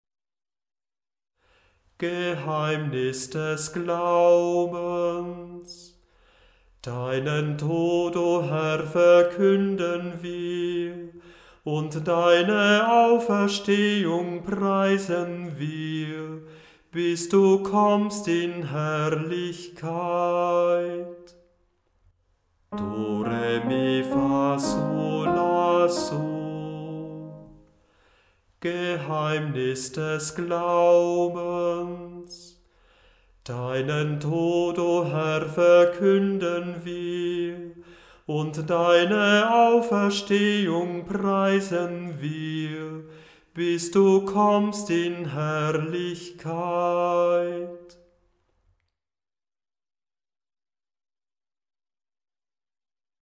Die Hörbeispiele sind in der Tonhöhe absteigend geordnet.